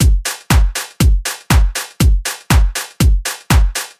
hier auch ein quick-entharschungsversuch mit dseq3
harsch.mp3